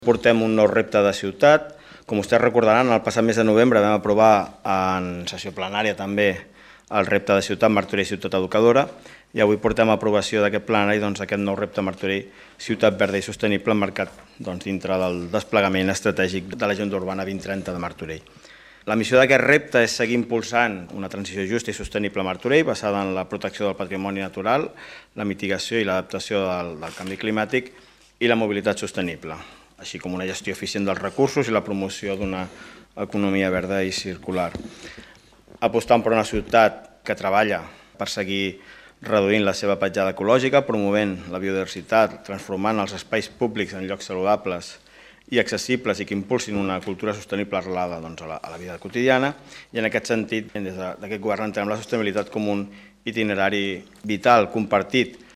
Albert Fernández, regidor d'Agenda Urbana i Planificació Urbanística